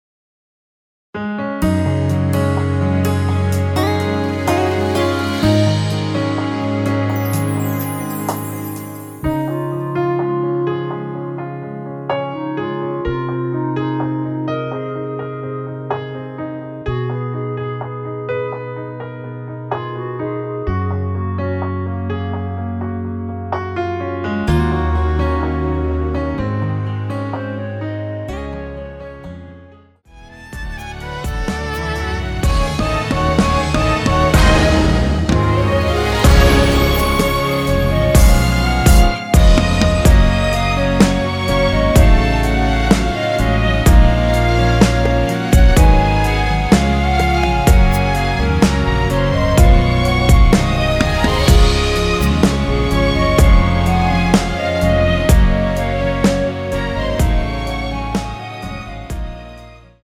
원키에서(-2)내린 멜로디 포함된 MR입니다.
Db
앞부분30초, 뒷부분30초씩 편집해서 올려 드리고 있습니다.
(멜로디 MR)은 가이드 멜로디가 포함된 MR 입니다.